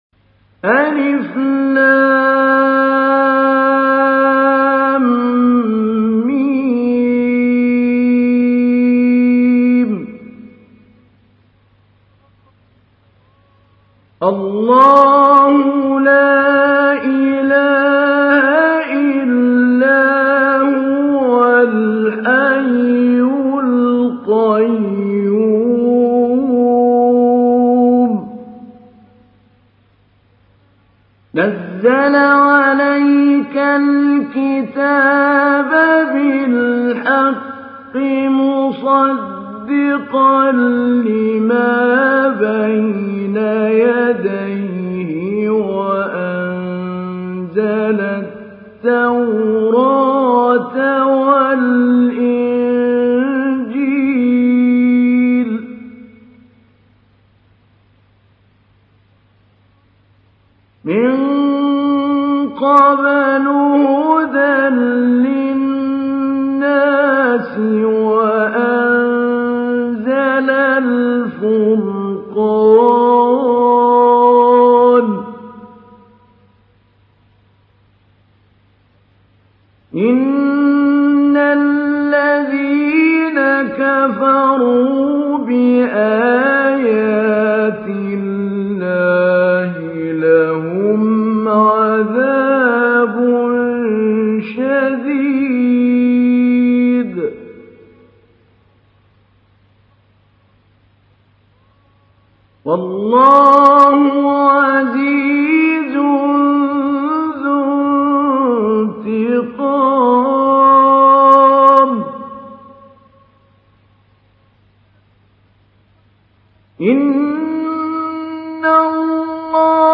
تحميل : 3. سورة آل عمران / القارئ محمود علي البنا / القرآن الكريم / موقع يا حسين